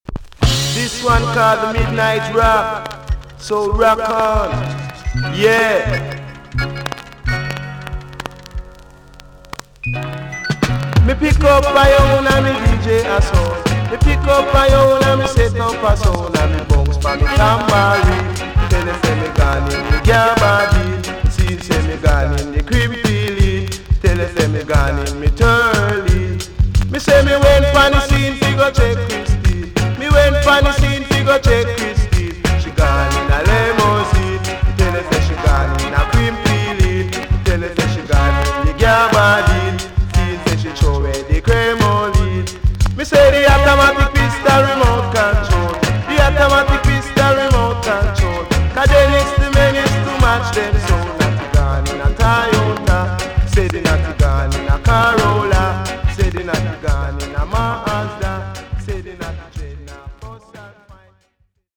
TOP >80'S 90'S DANCEHALL
VG+ 少し軽いチリノイズが入ります。
NICE TOASTING STYLE!!